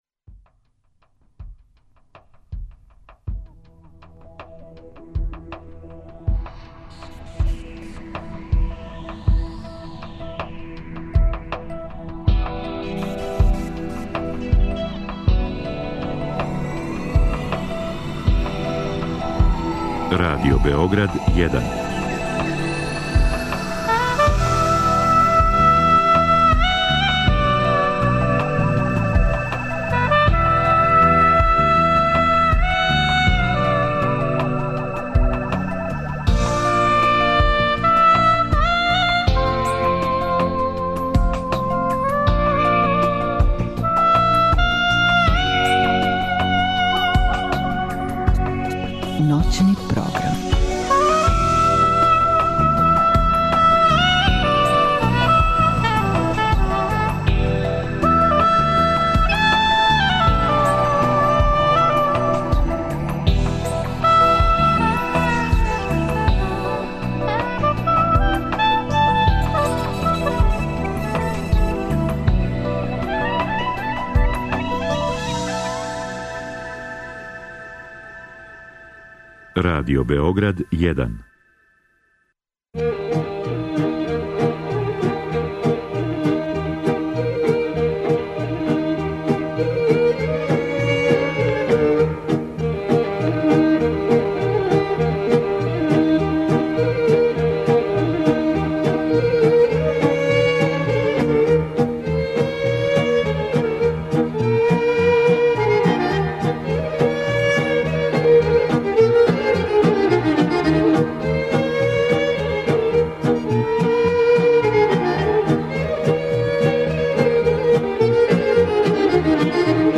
Слушаоци су будни и говоре... Почеци стварања Народног оркестра Радио-Београда и шта нам се ту не слаже...